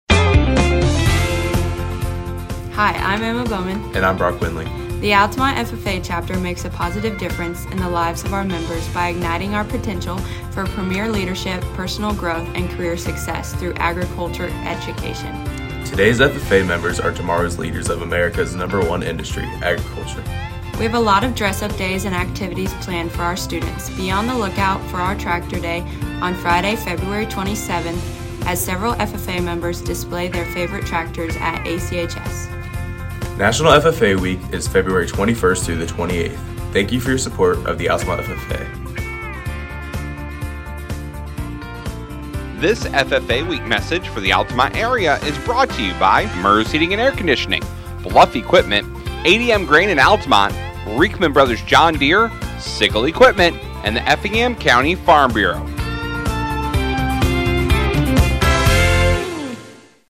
ffa-week-male-female-ef-altamont.mp3